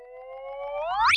reply_send.ogg